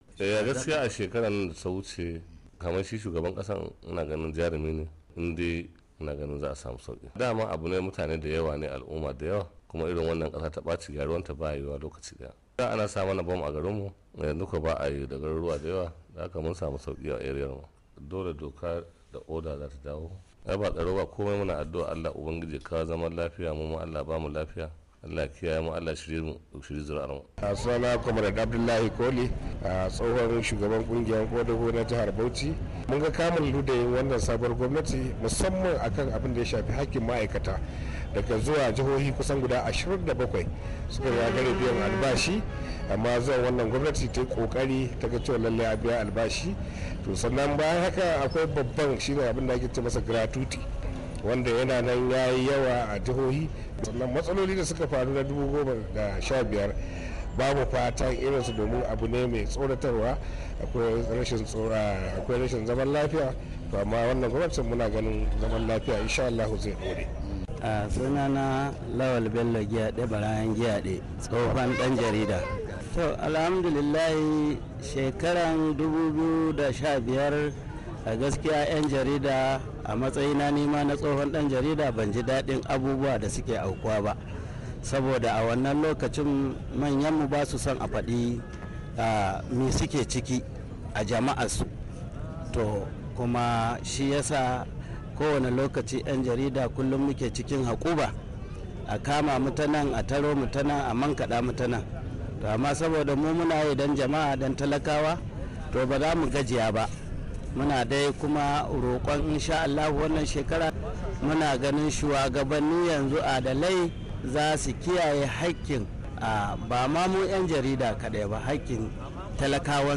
rahoton cikin murya.